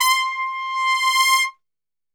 C 4 TRPSWL.wav